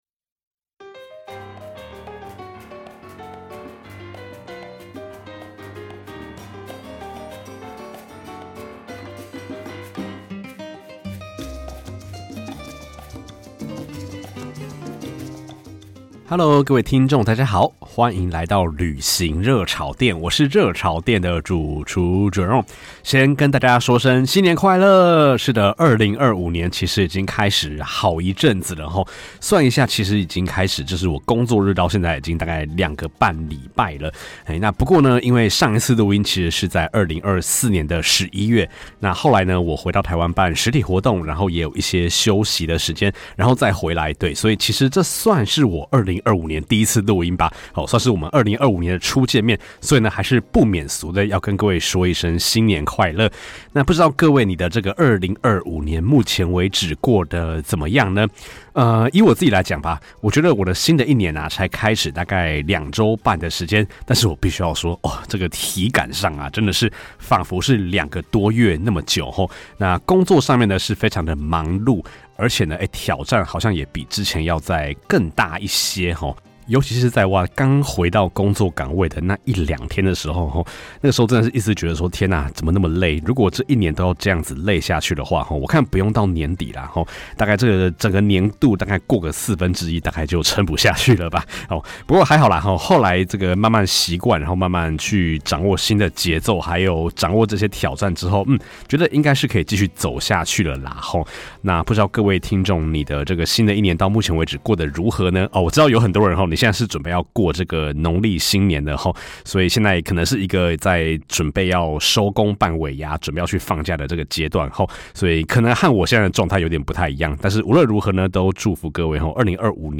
基層社畜一枚，用下班後的剩餘精力旅行與做節目，大多數的單集都是自己講的。